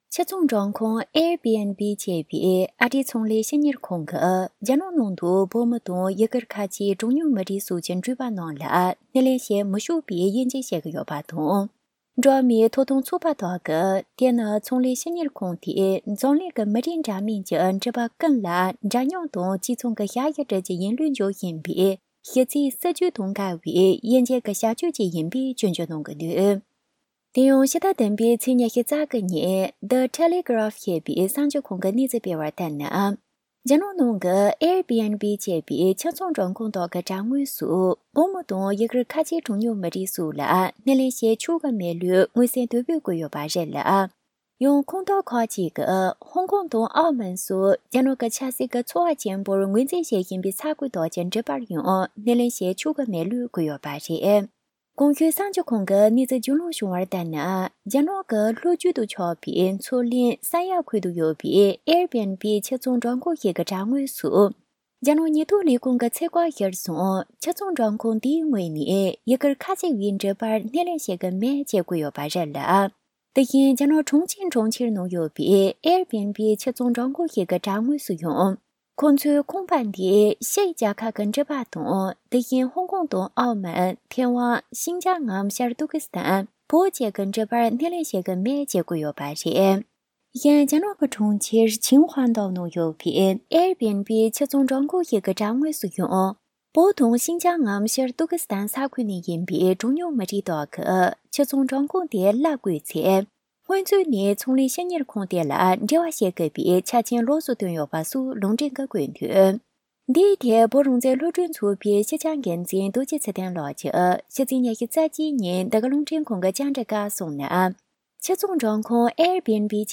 འབྲེལ་ཡོད་མི་སྣ་ཁག་གཅིག་ལ་གནས་ཚུལ་བཅར་འདྲི་དང་ཕྱོགས་བསྡུས་ཞུས་པར་གསན་རོགས།